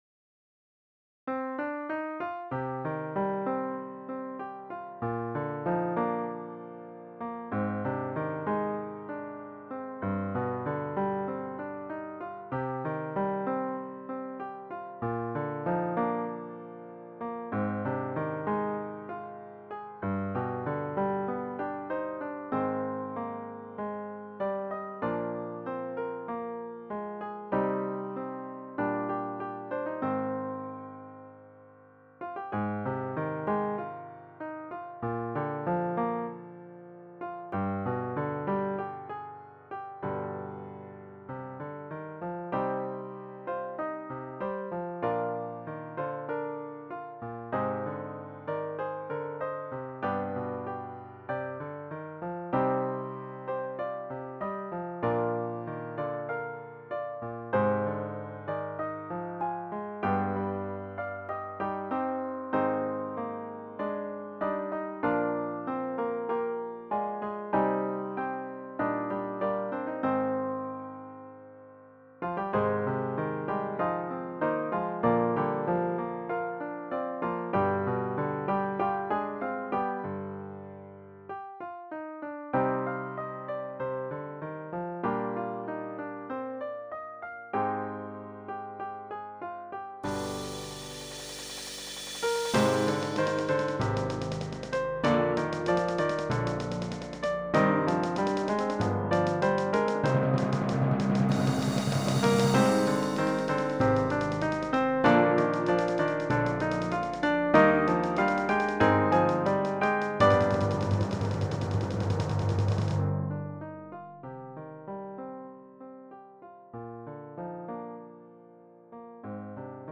Bezetting : Brassband Soort werk : Kerst